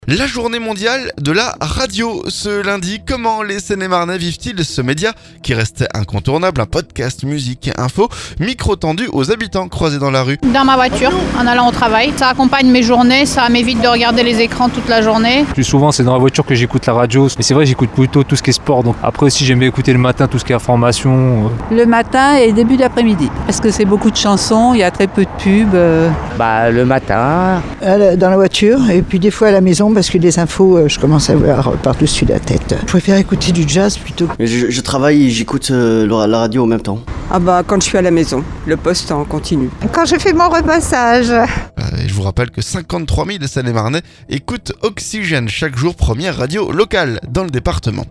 Micro tendu aux habitants croisés dans la rue.